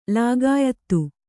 ♪ lāgāyattu